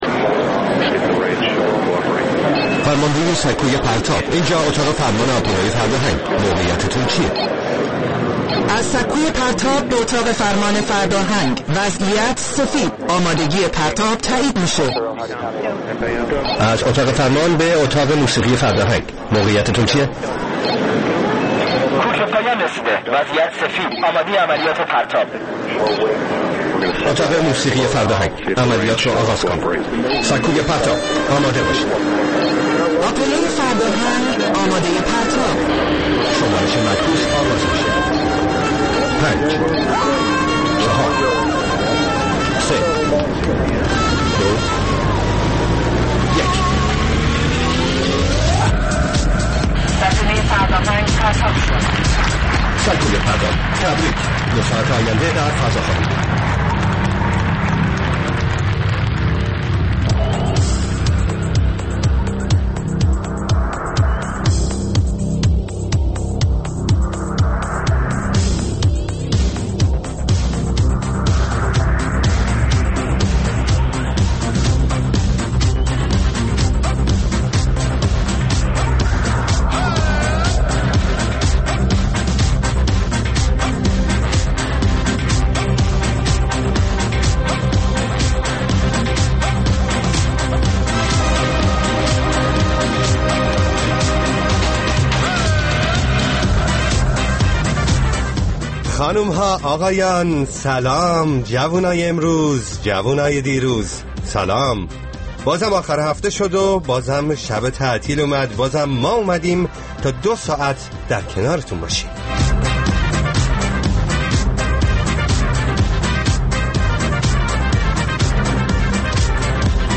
برنامه زنده موسیقی